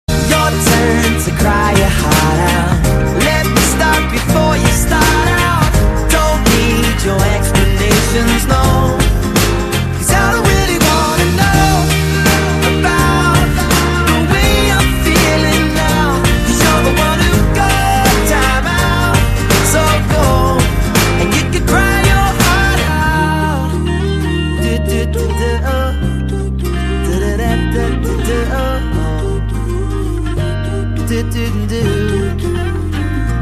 M4R铃声, MP3铃声, 欧美歌曲 69 首发日期：2018-05-15 14:46 星期二